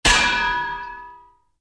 File:Anvil fall on head 1.oga
Sound effect used in Donkey Kong Country (Game Boy Advance), Donkey Kong Country 2 (Game Boy Advance), Donkey Kong Country 3 (Game Boy Advance), Donkey Kong 64, Donkey Kong Country Returns, and Donkey Kong Country: Tropical Freeze.
Anvil_fall_on_head_1.oga.mp3